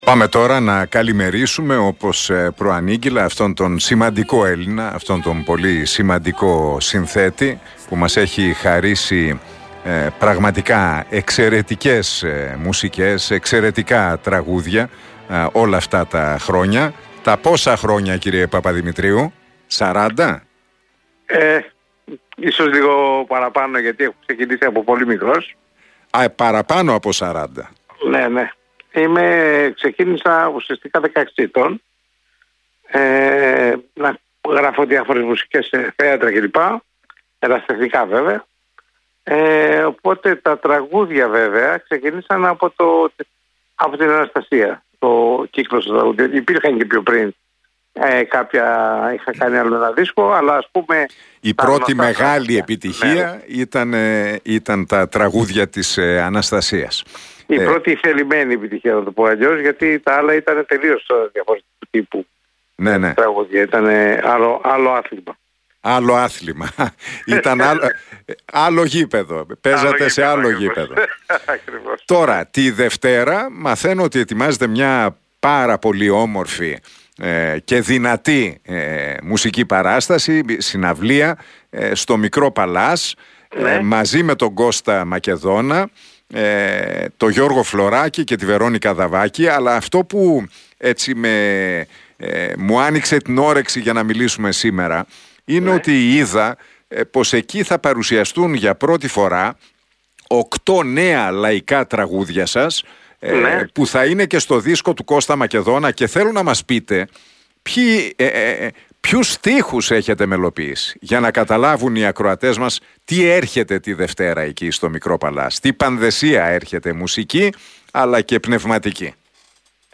Ο Δημήτρης Παπαδημητρίου μίλησε στην εκπομπή του Νίκου Χατζηνικολάου στον Realfm 97,8 για την συναυλία της Δευτέρας 20 Φεβρουαρίου.